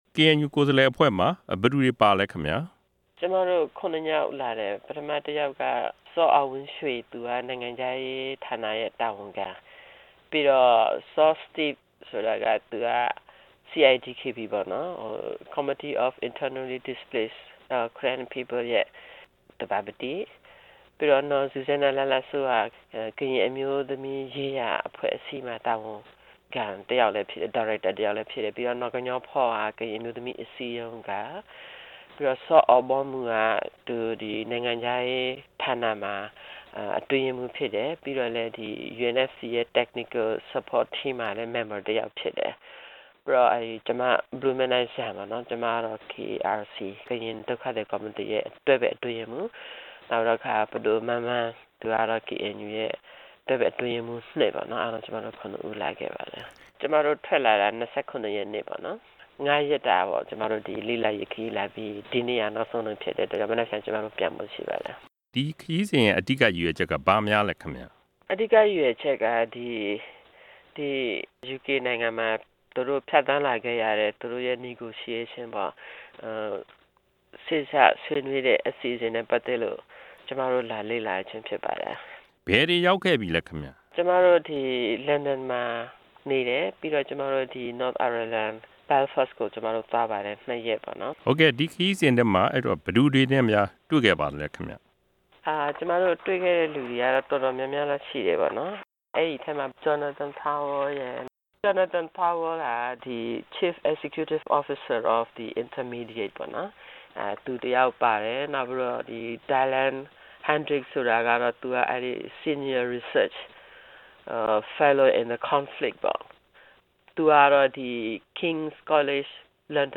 RFA က ဆက်သွယ်မေးမြန်းထားပါတယ်။